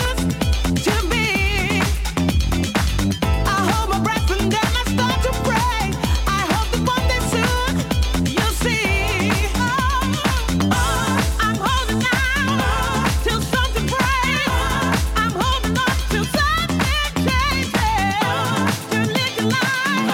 FM-тюнер
Качество приёма также не вызвало нареканий, однако здесь свою роль могло сыграть использование коллективной антенны.
Тюнер обеспечивает хорошее качество звука в FM-режиме (